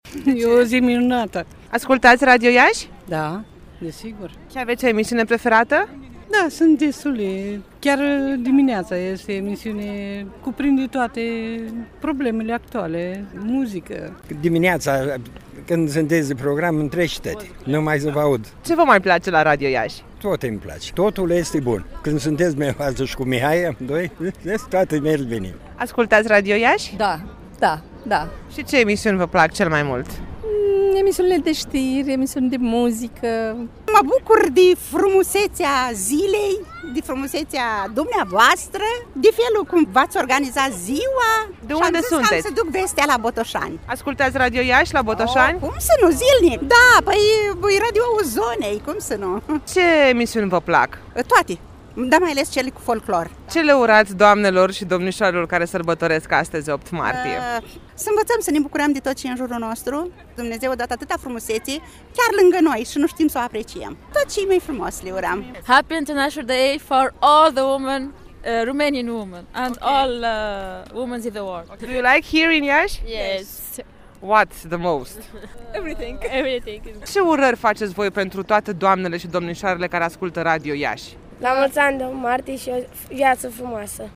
În Piața Unirii ne-am simțit extraordinar alături de voi:
8-martie-vox.mp3